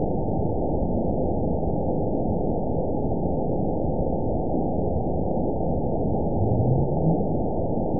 event 920324 date 03/16/24 time 18:16:21 GMT (1 year, 1 month ago) score 9.47 location TSS-AB03 detected by nrw target species NRW annotations +NRW Spectrogram: Frequency (kHz) vs. Time (s) audio not available .wav